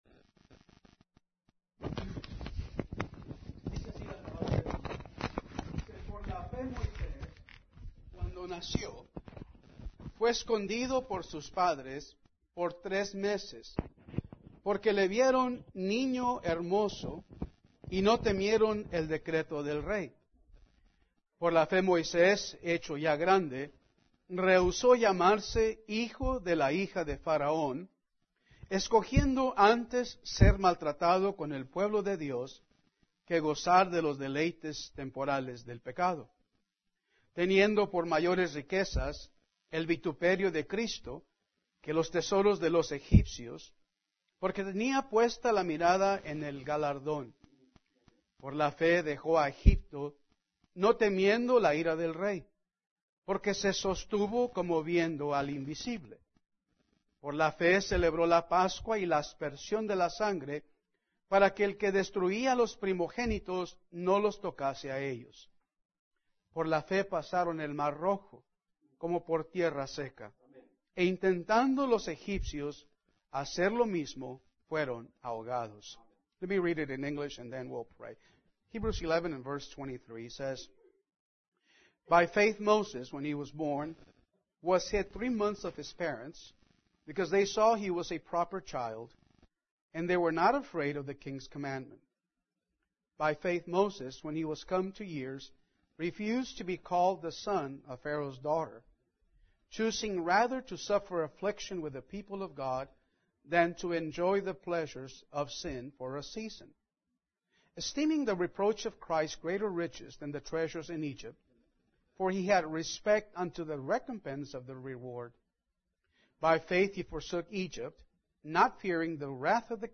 Domingo por la mañana – Hebreos 11